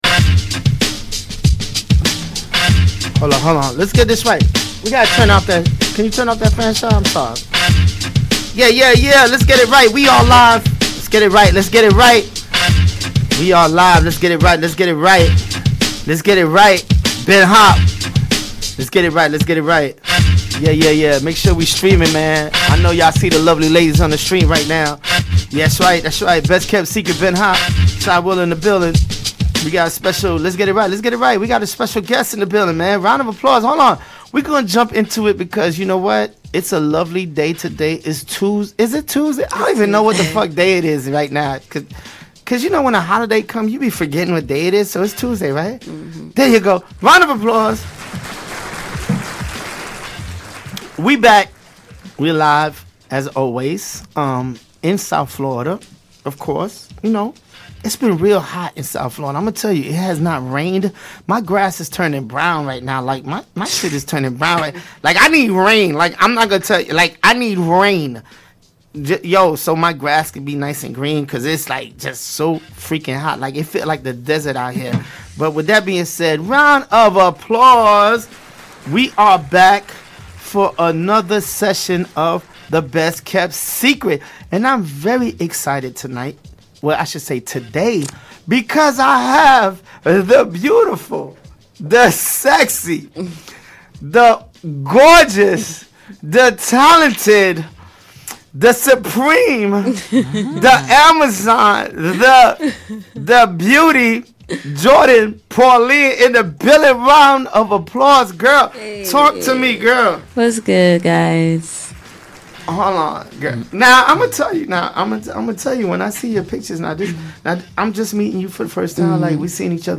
Showcasing Independent Artist from all over the world.